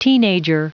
Prononciation du mot teenager en anglais (fichier audio)
Prononciation du mot : teenager